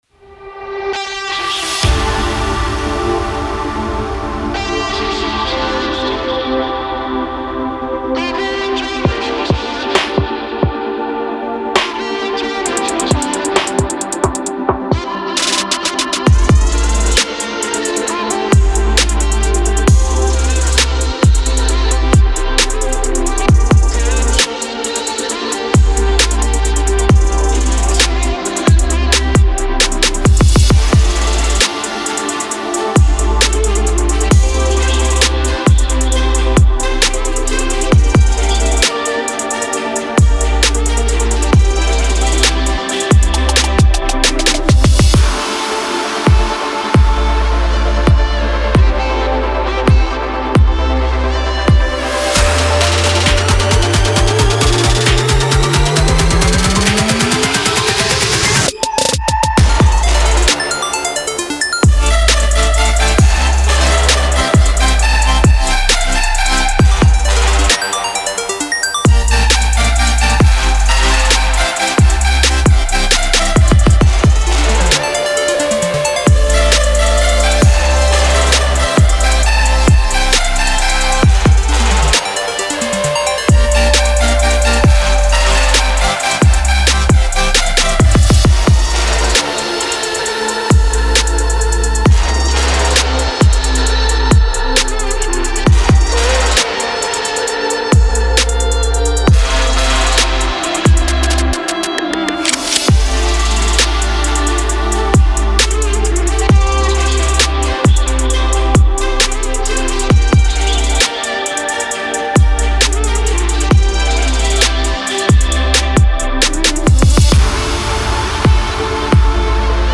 Trap, Quirky, Weird, Gloomy, Dark